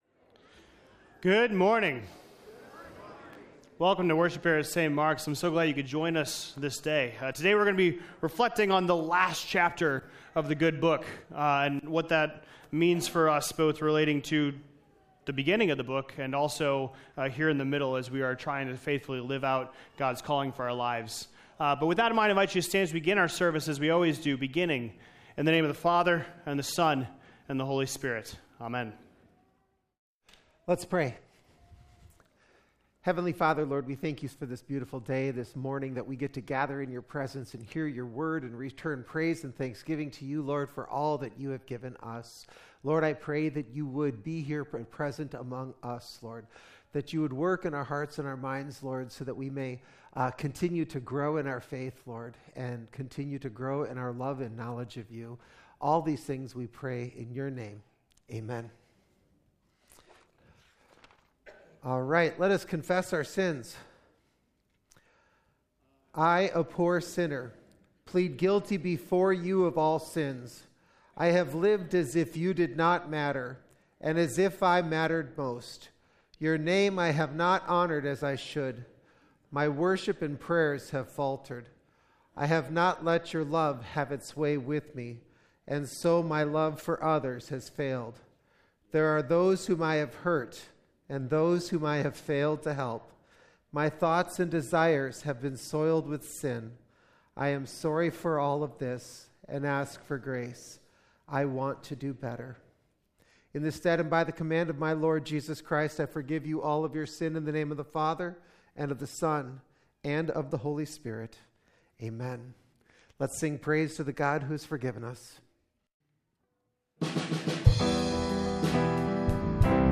2025-June-1-Complete-Service.mp3